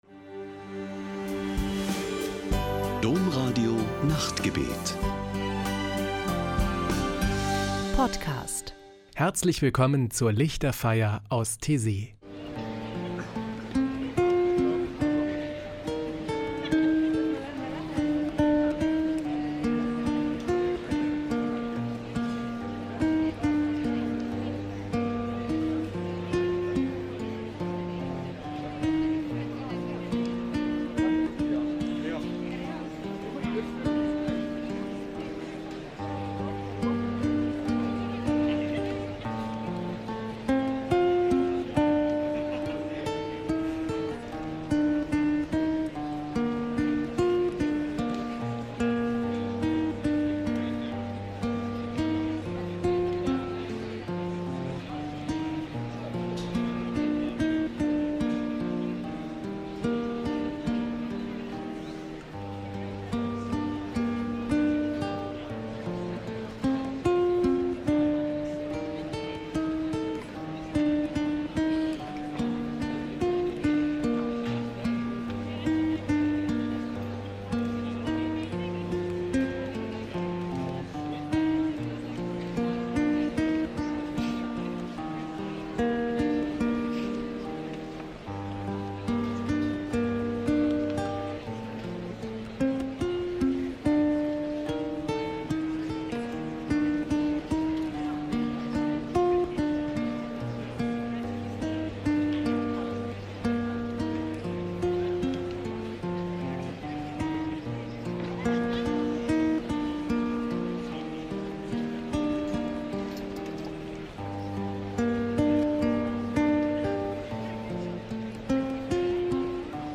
Die Lichterfeier aus Taizé: Spirituelle Gesänge und Gebete
Ein Höhepunkt jede Woche ist am Samstagabend die Lichterfeier mit meditativen Gesängen und Gebeten.